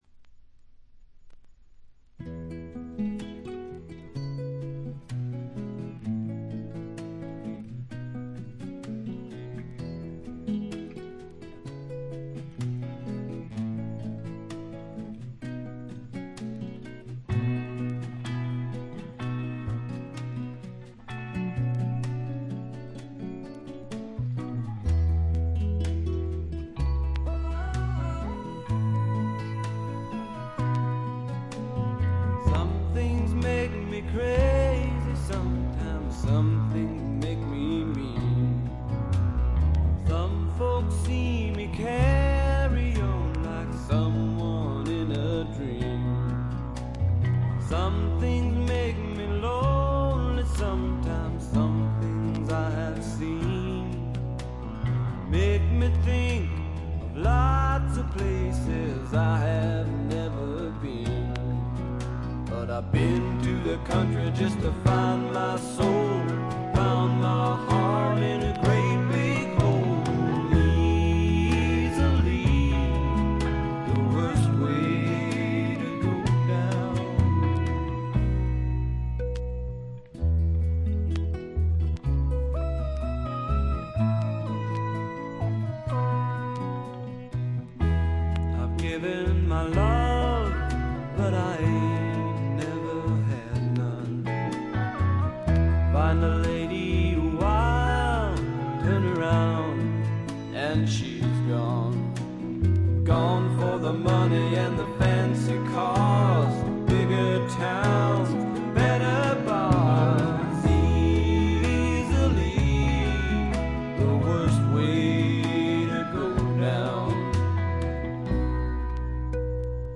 B2で少しノイズが目立ちますが鑑賞を妨げるようなものではありません。
試聴曲は現品からの取り込み音源です。
Recorded at Larrabee Sound , Holywood , California